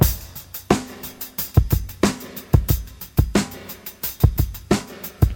• 89 Bpm Breakbeat Sample B Key.wav
Free breakbeat sample - kick tuned to the B note. Loudest frequency: 2483Hz
89-bpm-breakbeat-sample-b-key-4fS.wav